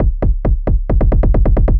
GAR Beat - Mix 6.wav